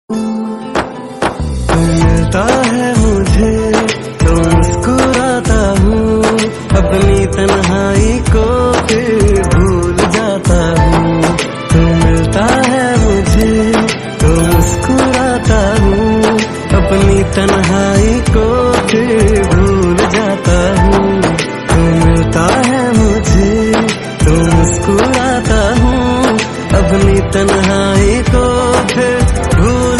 Trending Bollywood love-reunion melody hook
loud clean HD